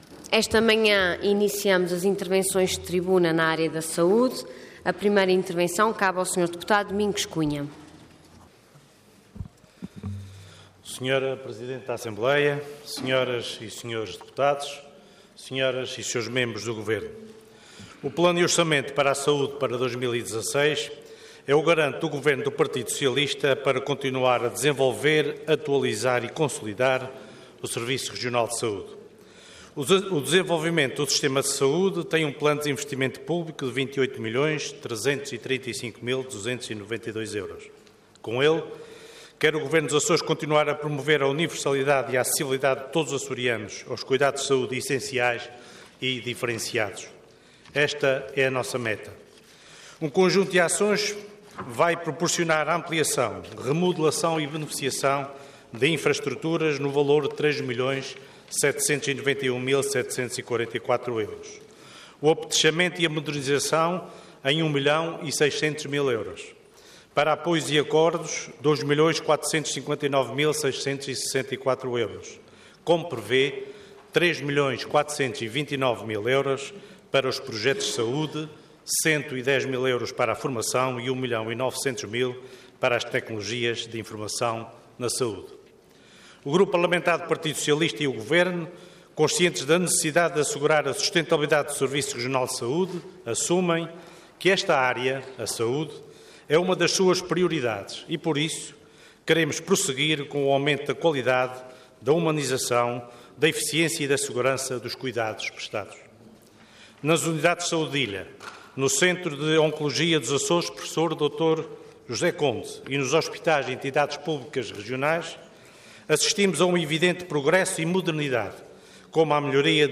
Detalhe de vídeo 25 de novembro de 2015 Download áudio Download vídeo X Legislatura Plano e Orçamento para 2016 - Saúde - Parte II Intervenção Proposta de Decreto Leg. Orador Luís Cabral Cargo Secretário Regional da Saúde Entidade Governo